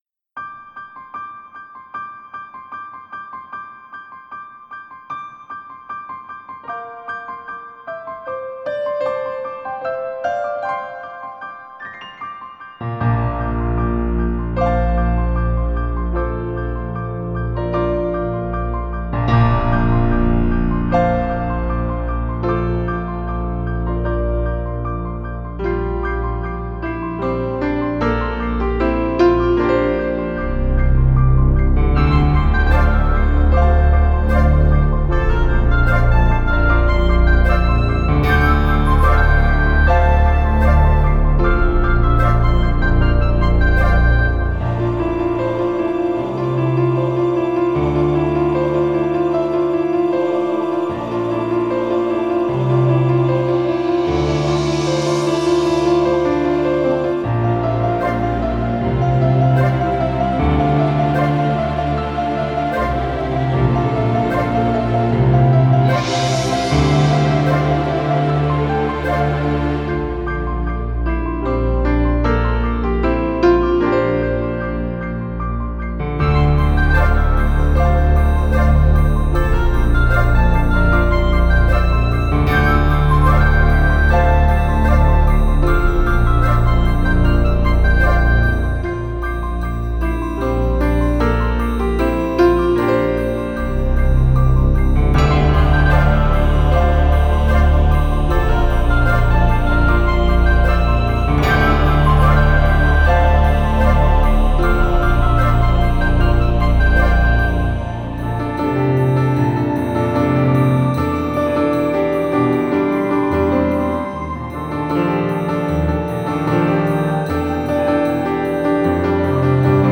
Un morceau que je trouve très reposant.